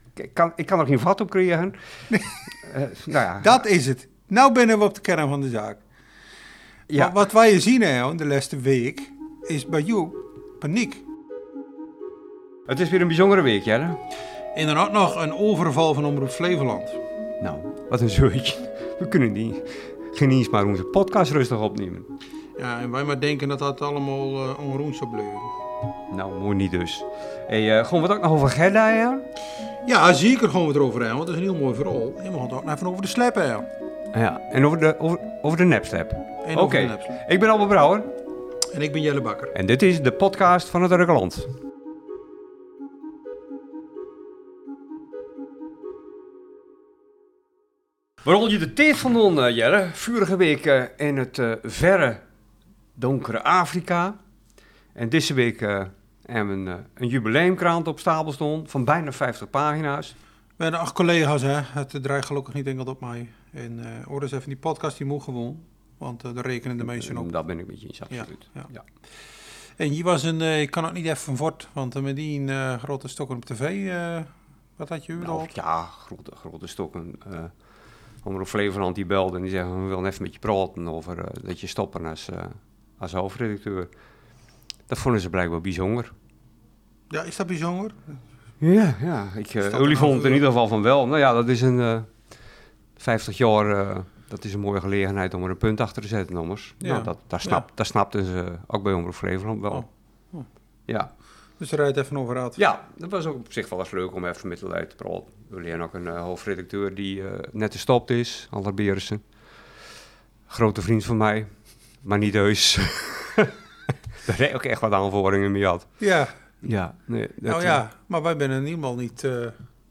De redactie van Het Urkerland was afgelopen dinsdag te vinden op een plek waar de hele dag door, zes dagen per week vele Urkers in- én uitlopen: de plaatselijke Albert Heijn.